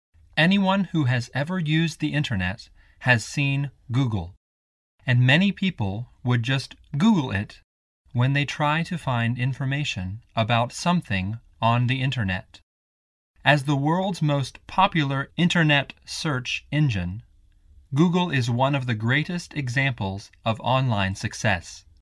课文朗读